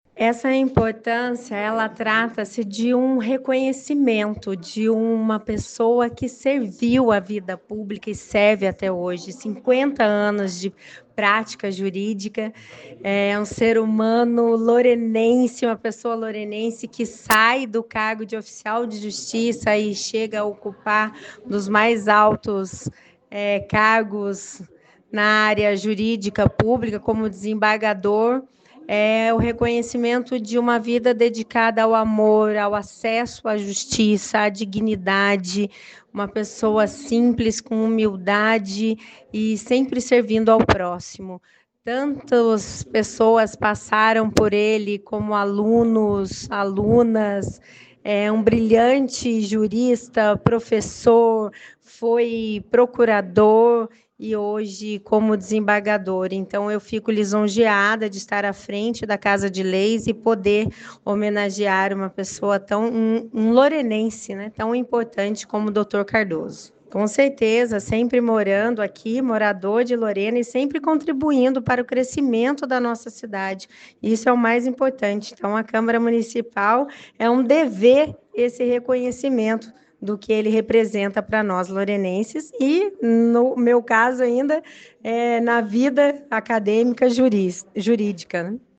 Homenagem foi entregue, na sessão desta segunda-feira (5), no plenário do Legislativo
Áudio da Dra. Élida Viera, vereadora e presidente da Câmara